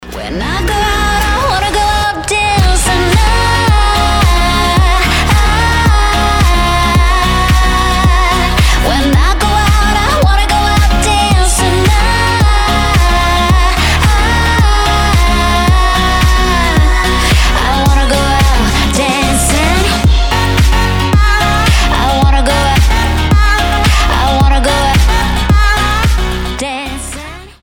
поп
женский вокал
заводные
dance